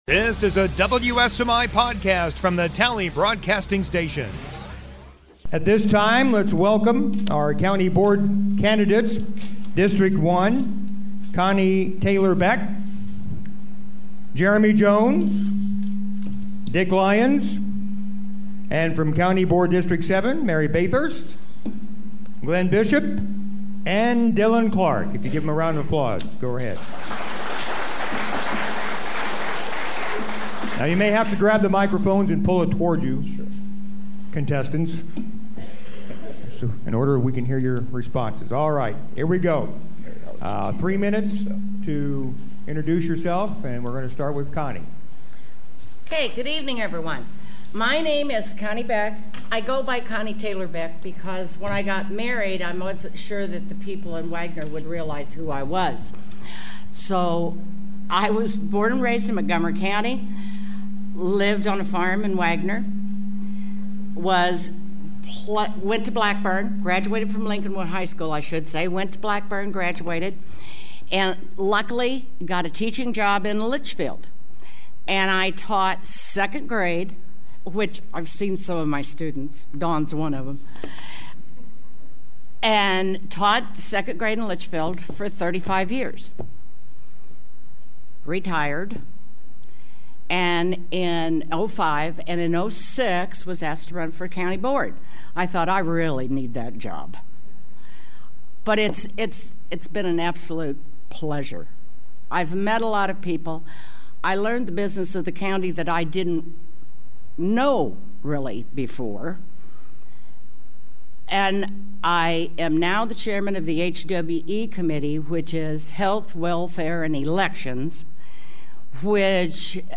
County Board Candidates Forum